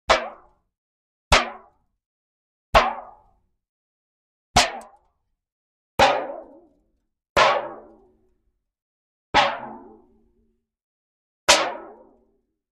Metal, Foil Crinkle; Foil Lightly Crinkles And Tinkles ( Aluminum Foil )